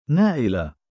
母音記号あり：نَائِلَة [ nā’ila(h) ] [ ナーイラ ]